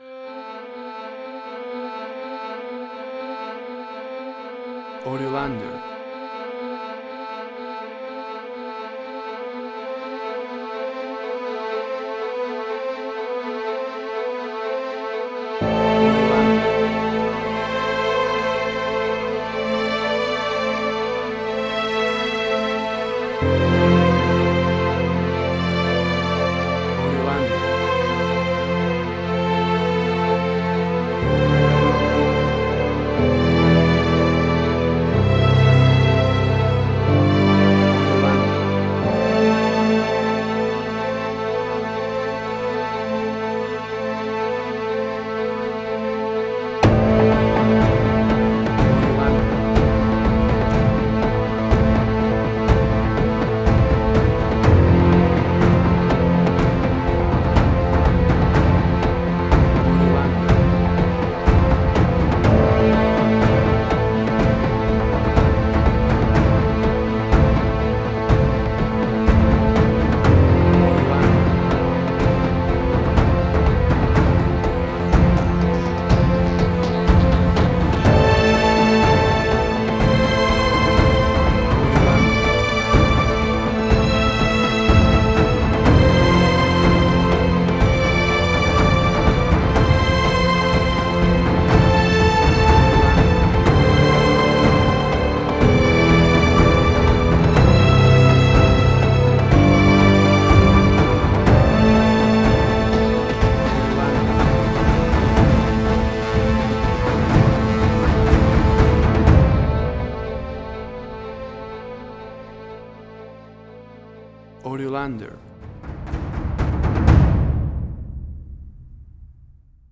Suspense, Drama, Quirky, Emotional.
Tempo (BPM): 120